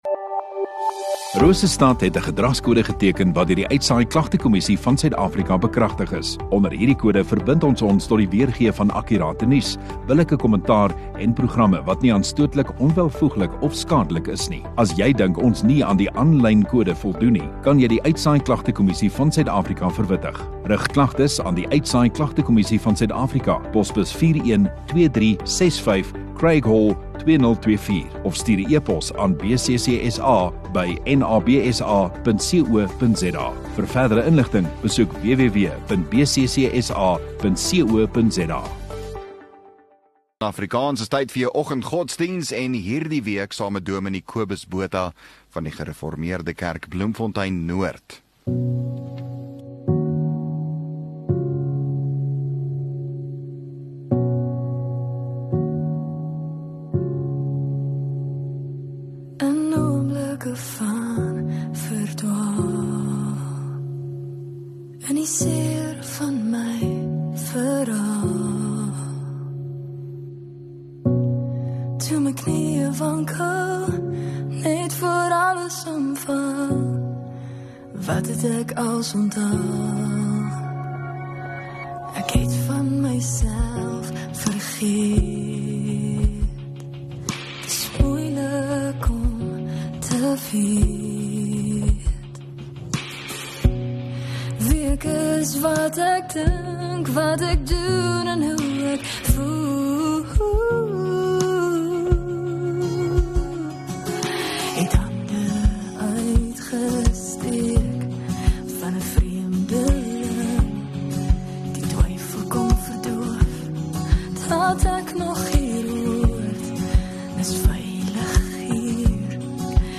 26 Jun Donderdag Oggenddiens